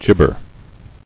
[gib·ber]